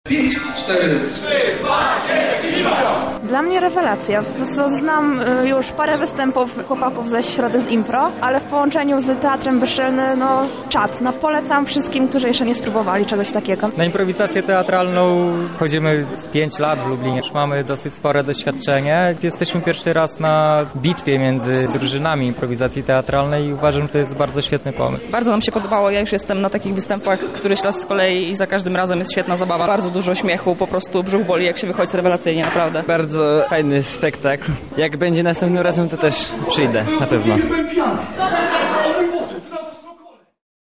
meczimpro-rela.mp3.mp3